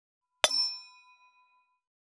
325チャン,クリン,シャリン,チキン,コチン,カチコチ,チリチリ,シャキン,
コップ